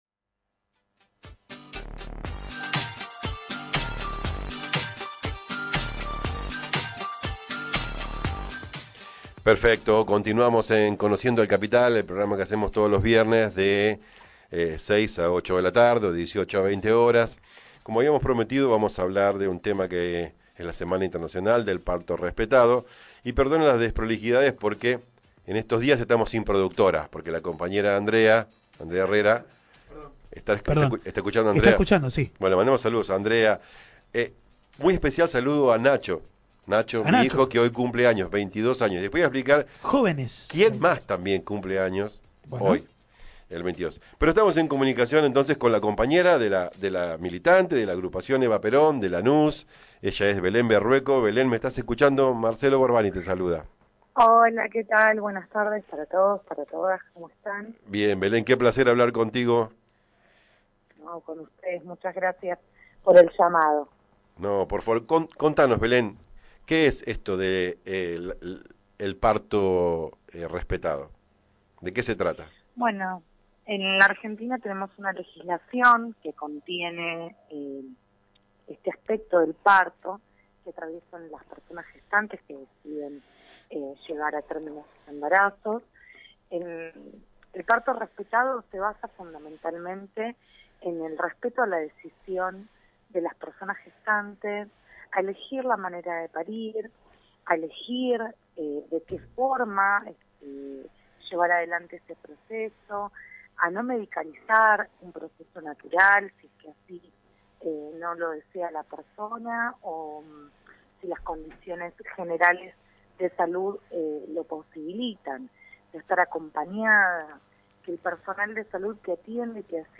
Escuchá la entrevista completa: Defendé el Proy de Ley Johana